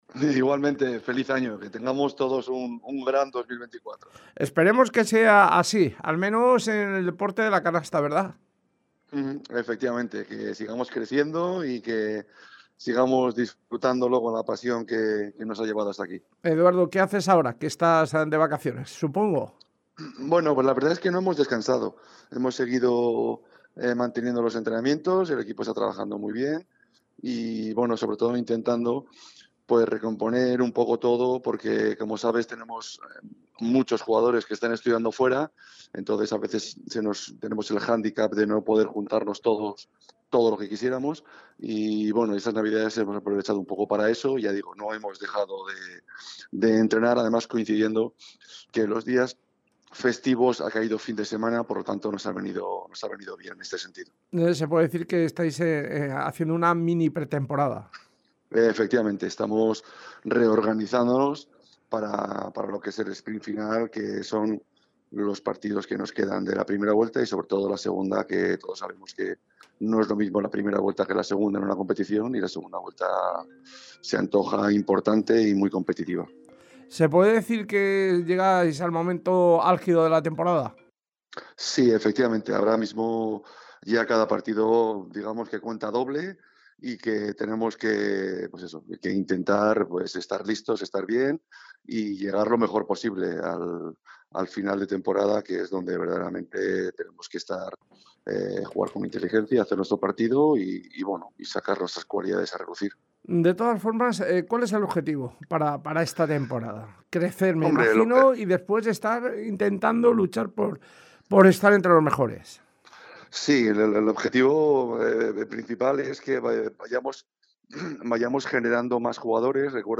Reyes Magos
entrevista